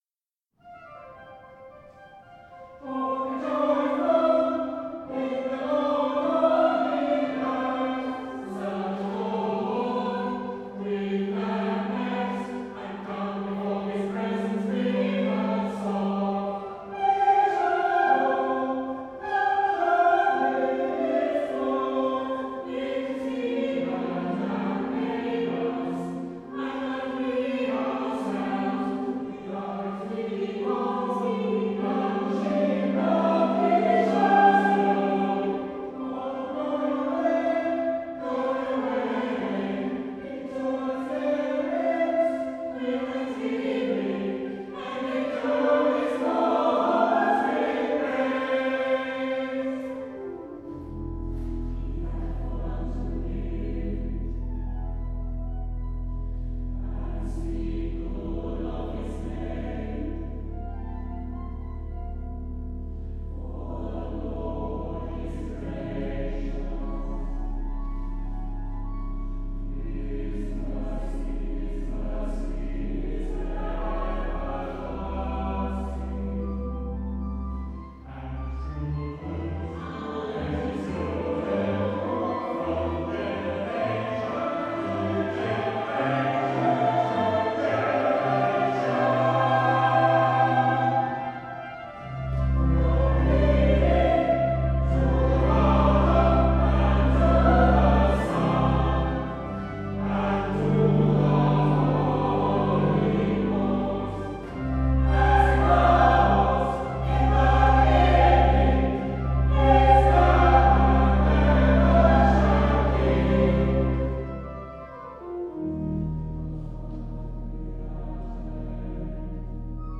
English choral music as it might have been sung
in the Priory Church through the centuries